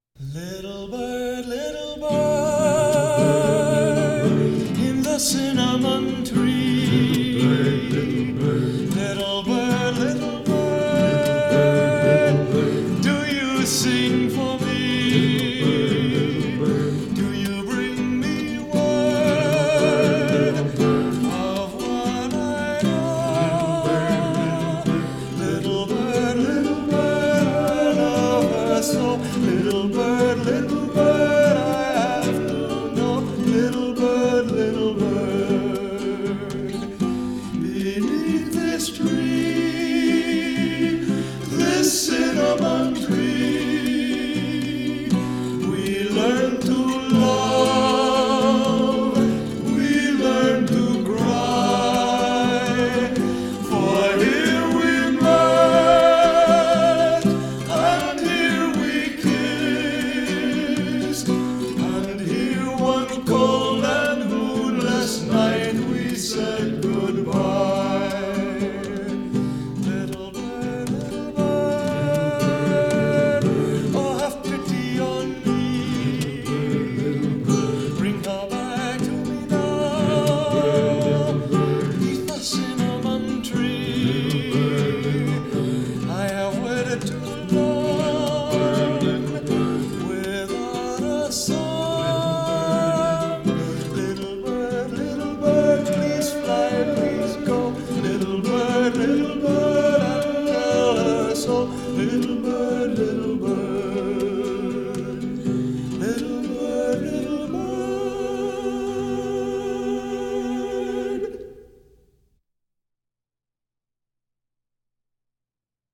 He sat at his desk in the Arts Center when we spoke.